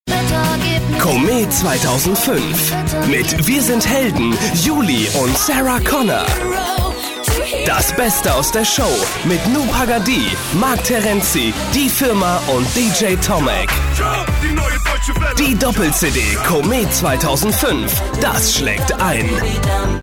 deutscher Sprecher, helle bis mittlere Stimmlage: gerne eingesetzt für Funk- und TV-Werbung, Off-Stimme, Voice Over
Kein Dialekt
Sprechprobe: eLearning (Muttersprache):